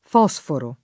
fosforo [ f 0S foro ] s. m. («elemento chimico»)